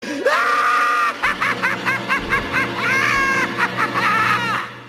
Joker Heehee Sound Button - Free Download & Play
Sound Effects Soundboard0 views